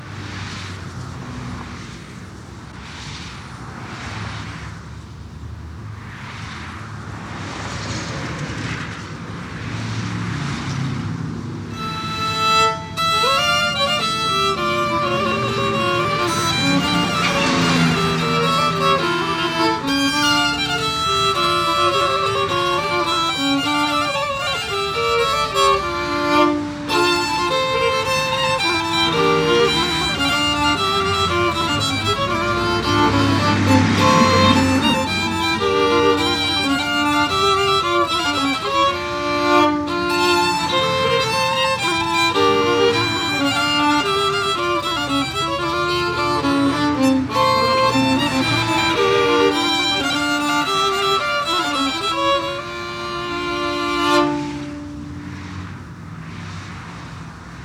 компенсируется скрипкой и клавишами, что, по правде говоря,